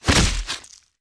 Melee Sword Sounds
Melee Weapon Attack 29.wav